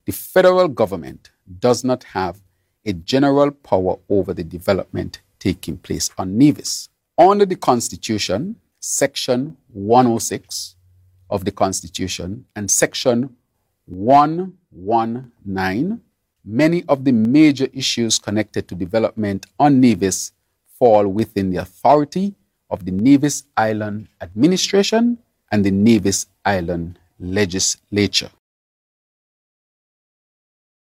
PM Drew issues statement on the proposal of the Destiny Project on Nevis
PM Drew explains.